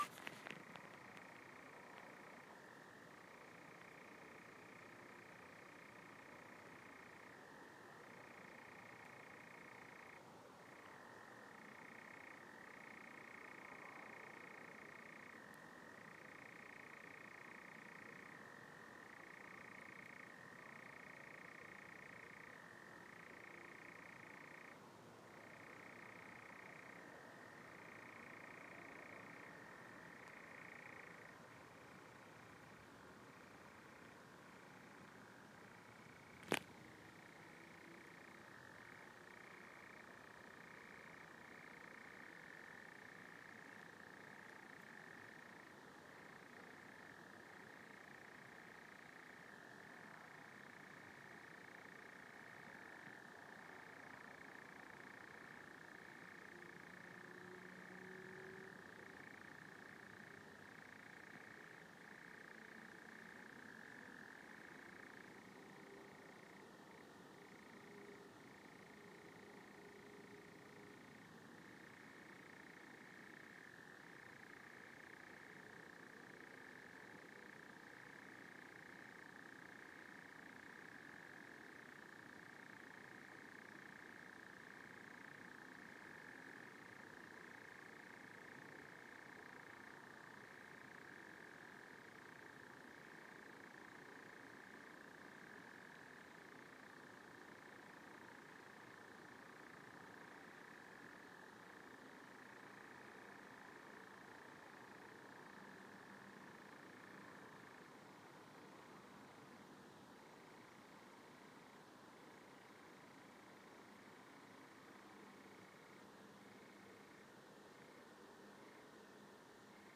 Nightjar on Whitmoor Common.
The male has a unique ‘churring’ call which can contain up to 1,900 notes a minute!